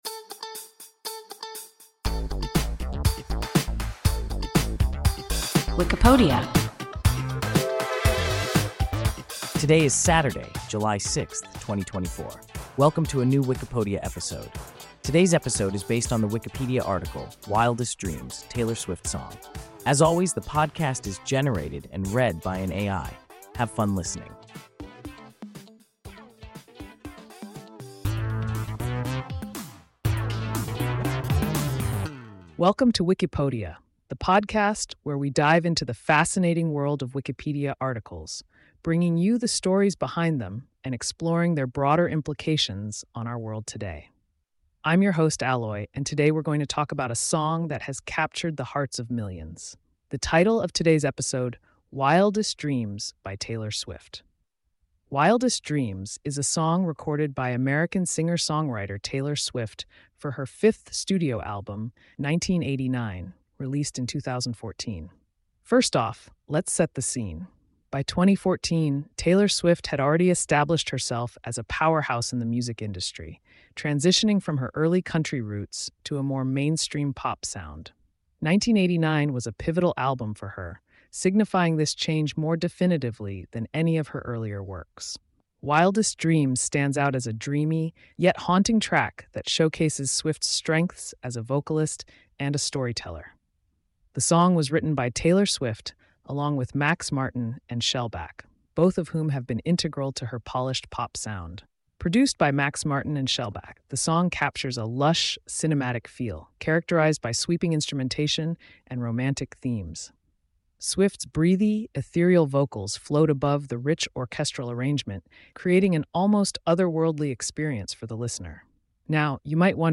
Wildest Dreams (Taylor Swift song) – WIKIPODIA – ein KI Podcast